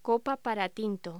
Locución: Copa para tinto
voz